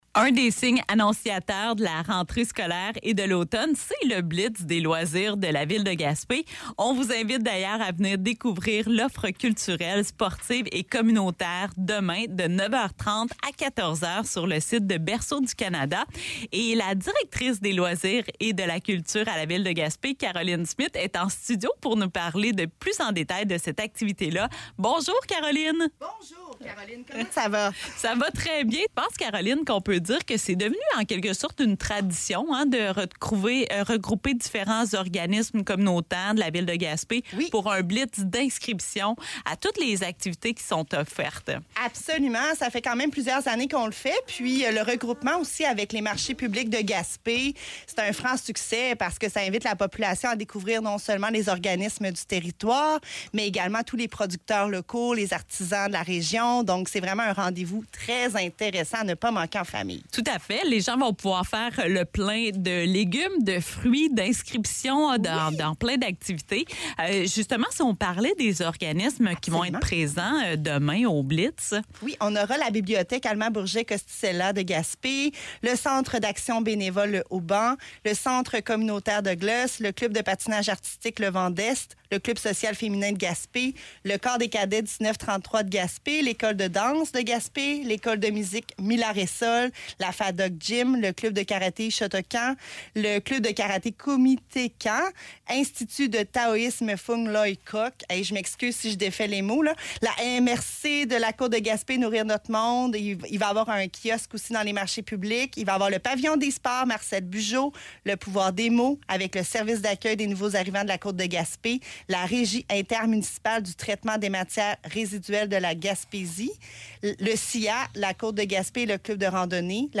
a profité de son passage en studio pour nous parler plus en détails du Blitz des loisirs.